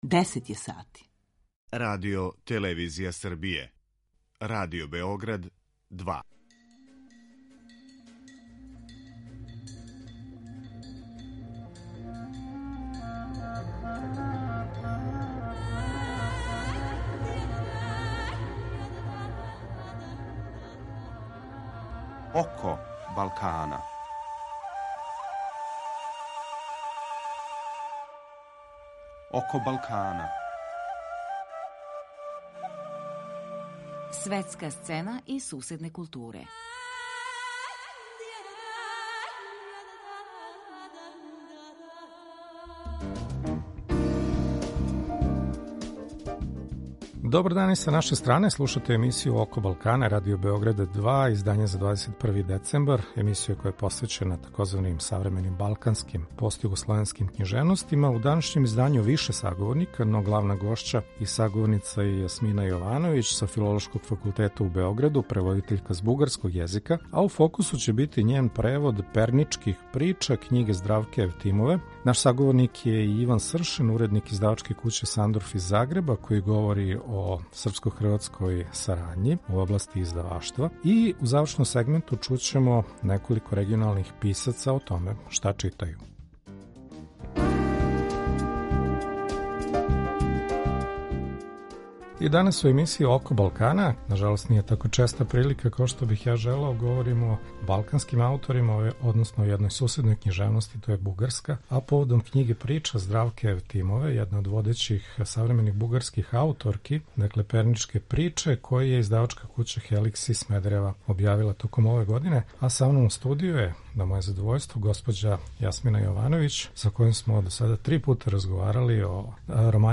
У завршном сегменту емисије чућемо шта чита неколико регионалних писаца различитих генерација и поетичких профила.